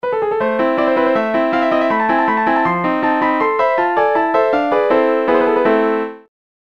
Rolandの MIDIキーボード(76鍵)
※わざとゲームぽく弾いています
[るんるん]ピアノのおけいこ7 （モーツァルトの「トルコ行進曲」から。160bpm）